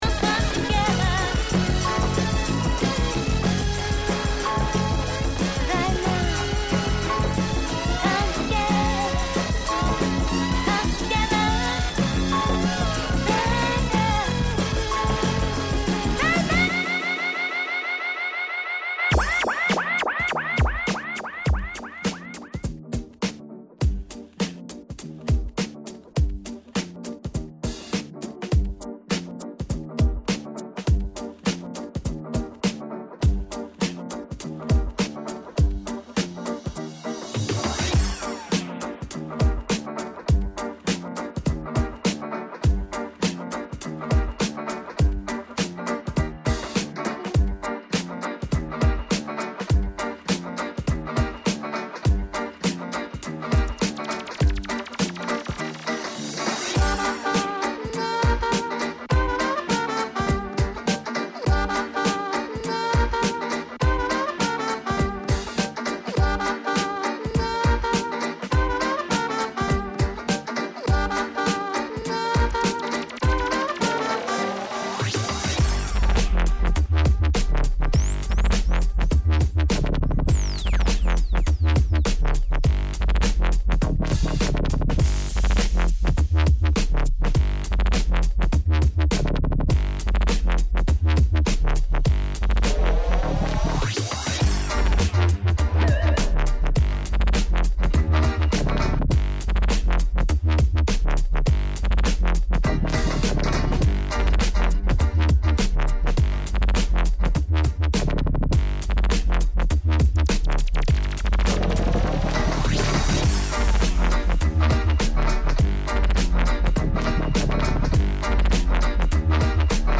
Electro Swing, Jazzy House, Funky Beats DJ for Hire